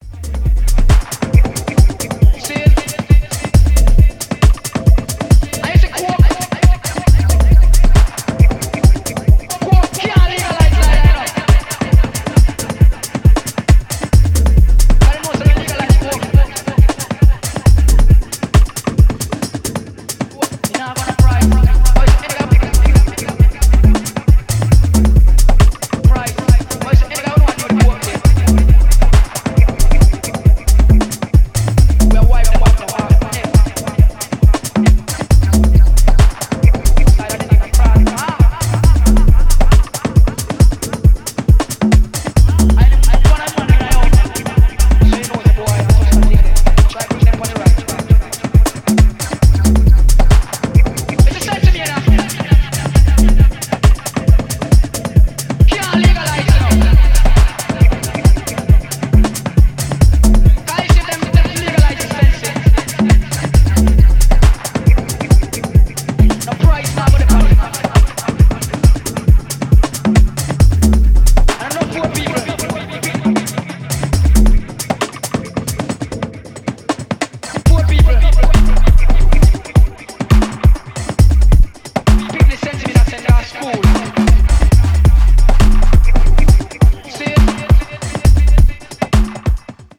伝統的ダブ・テクノの感覚を現代的なスタイルに落とし込んだ感もあり、ジャンルを跨いだフロアで作用してくれるはず。